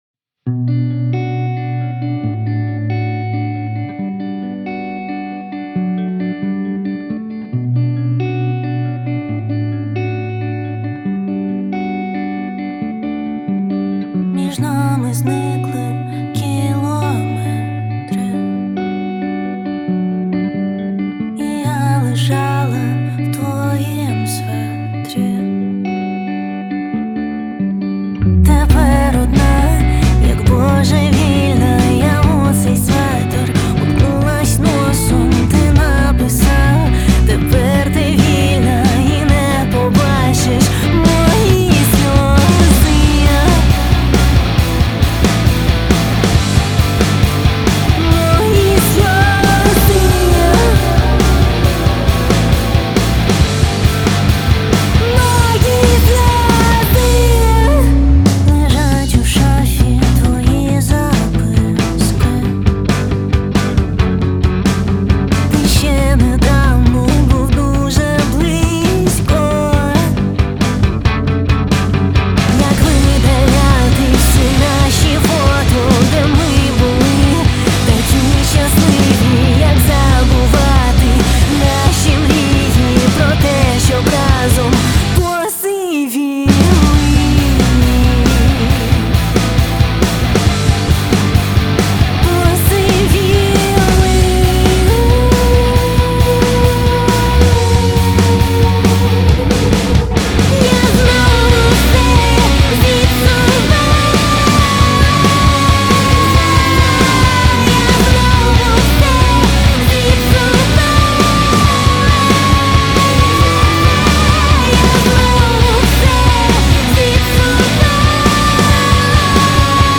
• Жанр: Rock